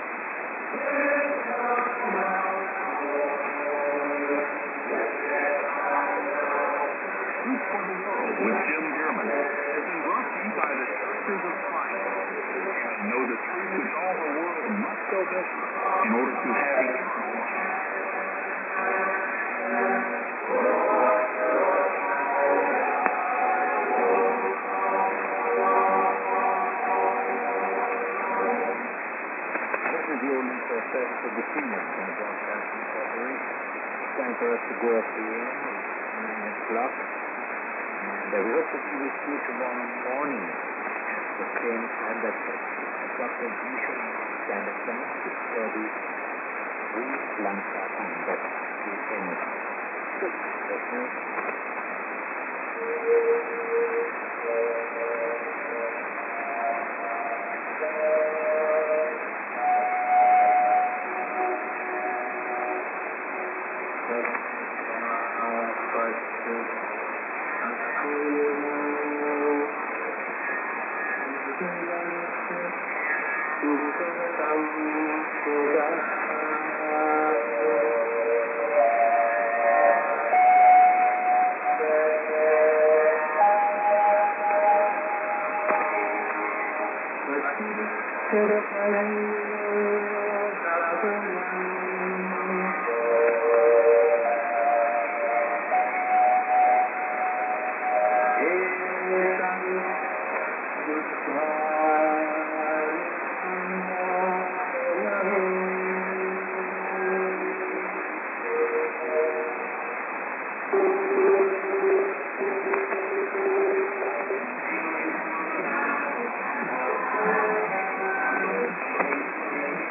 NA->29'25":ANN(man:ID)->NA->33': s/off